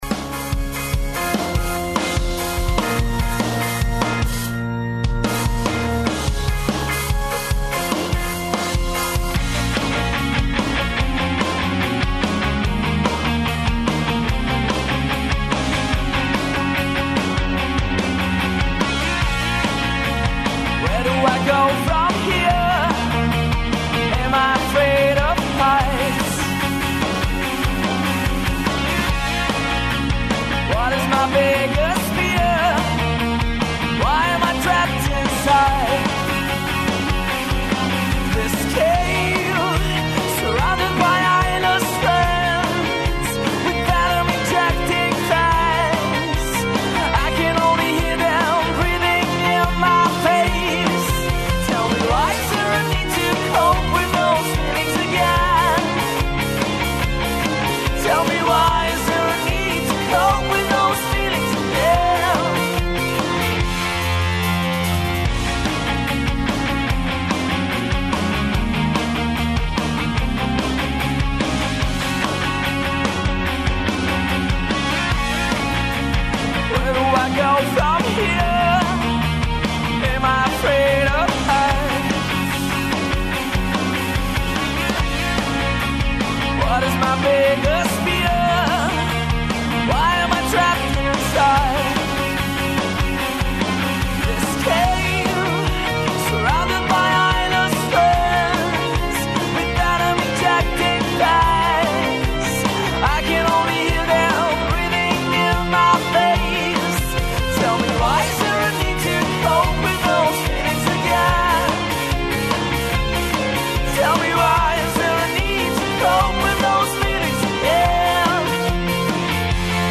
У студију су нам били гости, бенд „Гримус“ из Румуније који су наступили на бини Fusion, а нама су приредили свирку уживо!